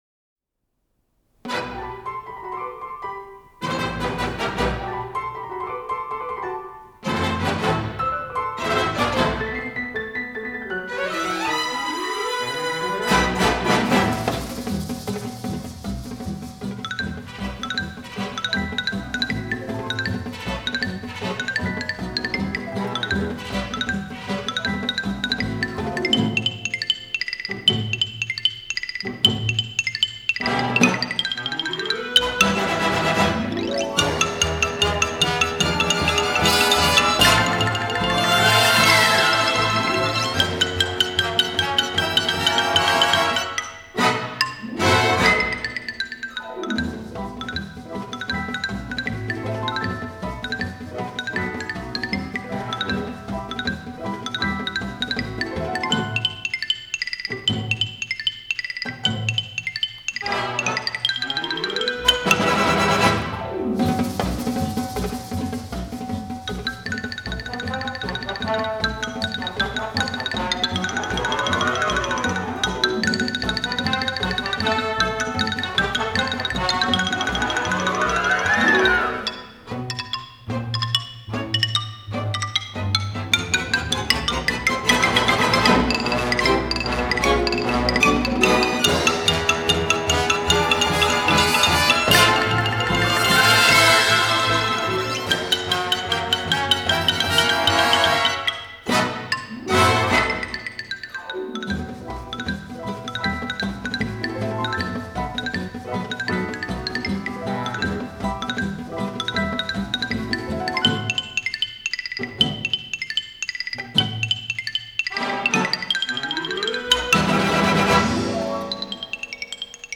ксилофон